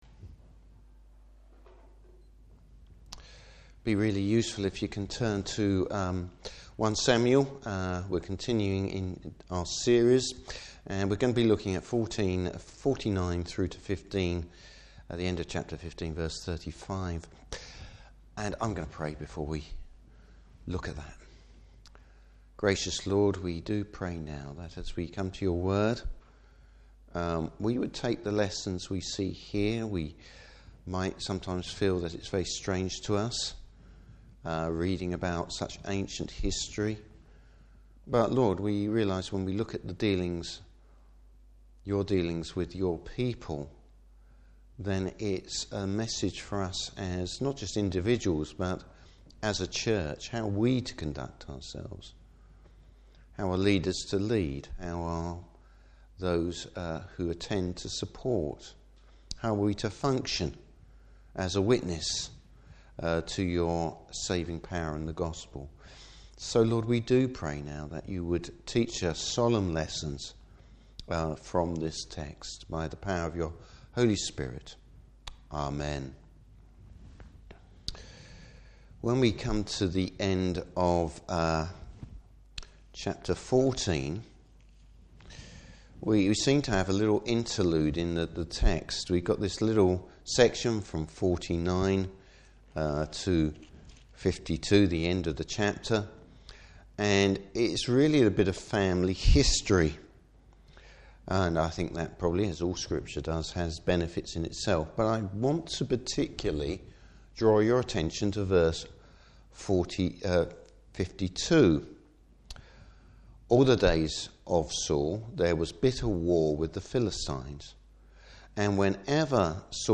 Service Type: Evening Service Saul tries to play fast and lose with God’s Word!